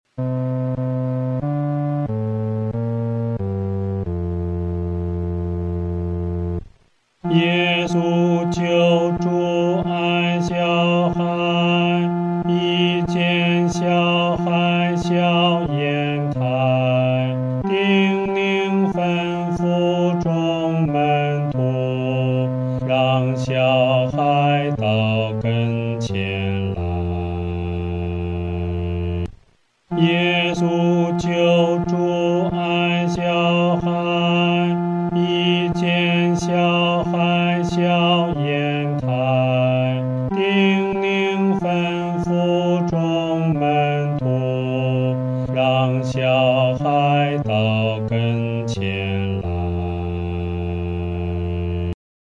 合唱
男低
这首诗歌可用较流动的中速来弹唱。